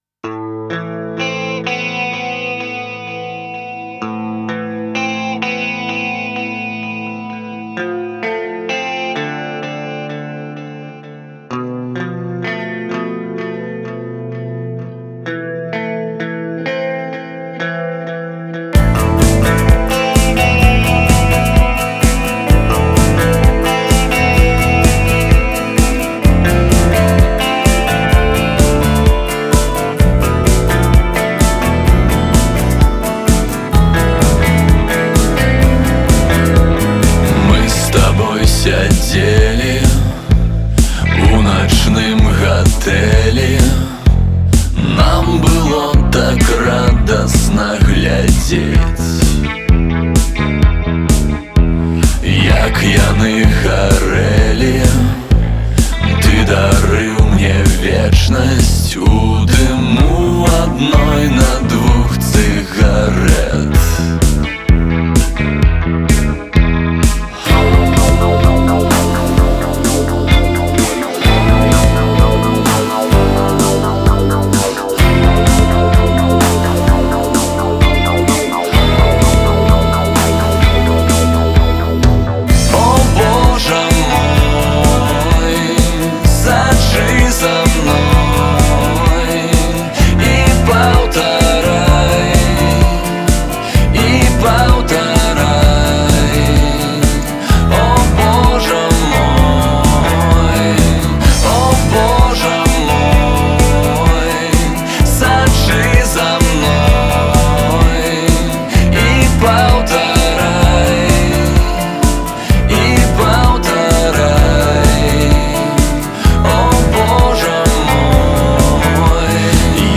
вакал, гітара
бас-гітара